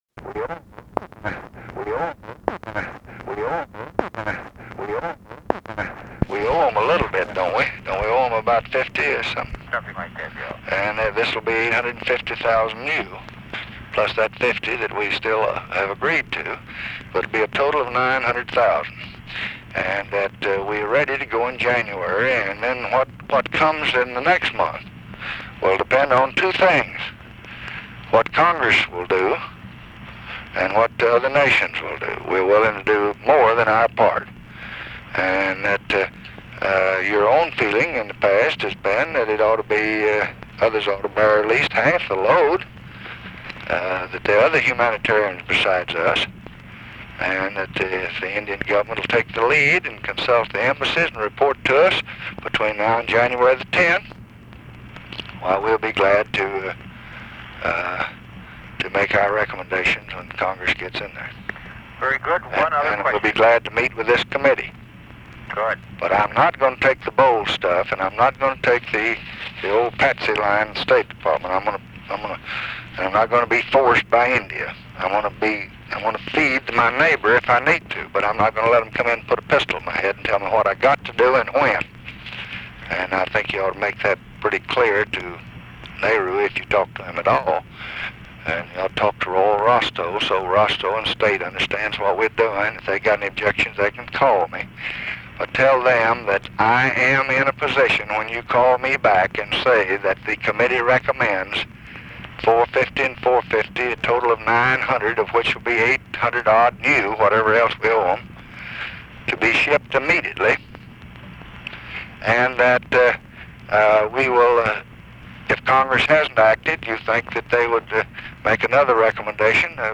Conversation with ORVILLE FREEMAN, December 22, 1966
Secret White House Tapes